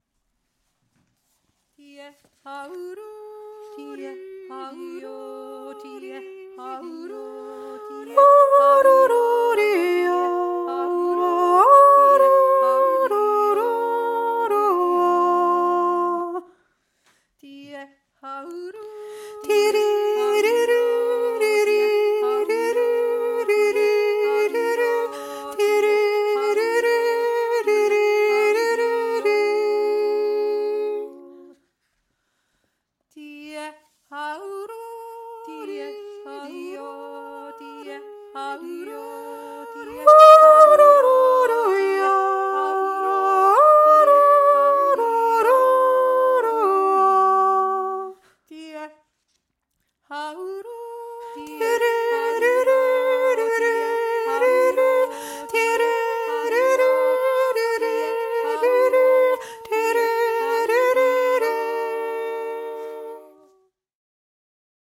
3. Stimme